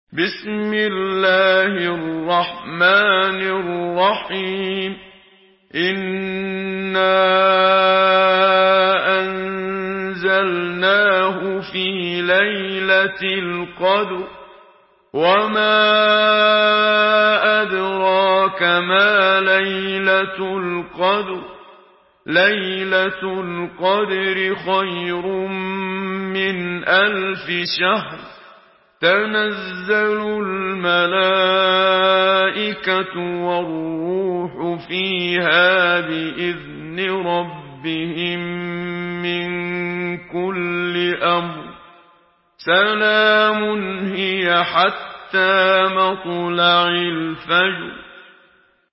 Surah Al-Qadr MP3 by Muhammad Siddiq Minshawi in Hafs An Asim narration.
Murattal